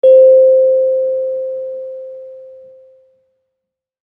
kalimba1_circleskin-C4-pp.wav